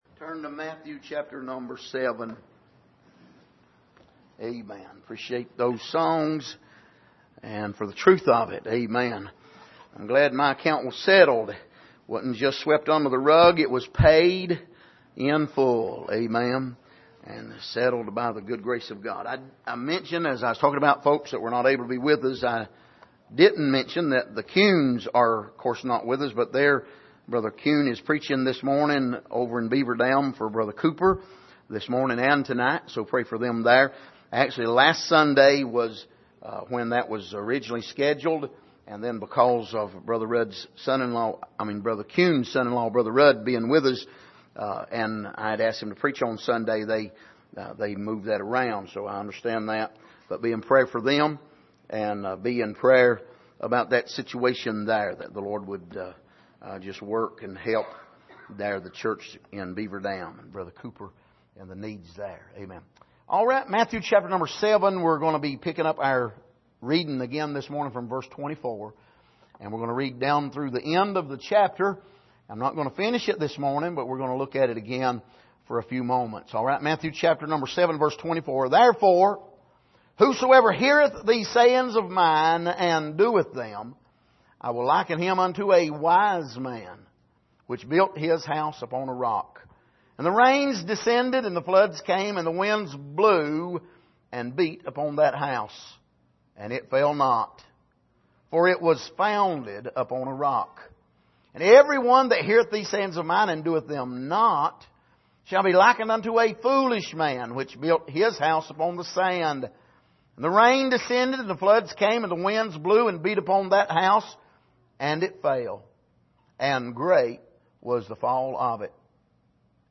Passage: Matthew 7:24-29 Service: Sunday Morning